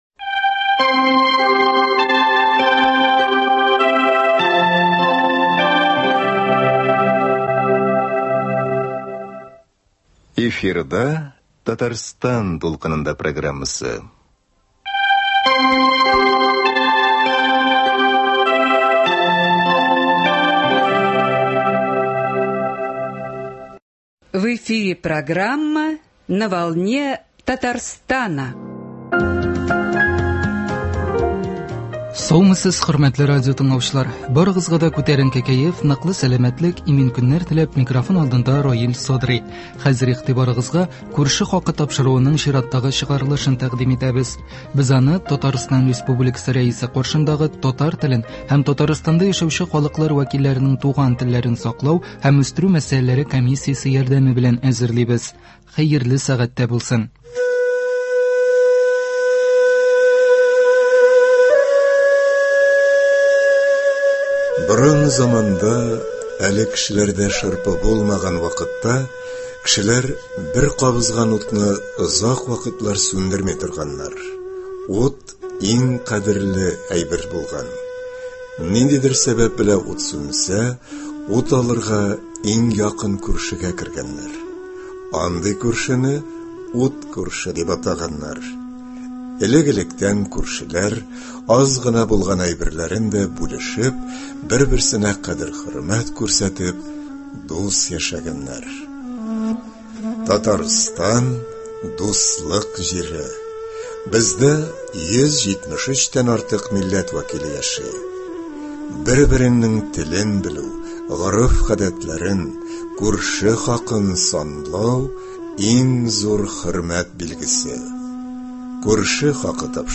Без бүген туган республикабызның иң күпмилләтле төбәгендә – Балтач районында. Биредә элек-электән татар, рус, удмурт, мари халкы бер-берсе белән Тукайча итеп әйтсәк, тел, лөгать һәм гадәт алмашып яши.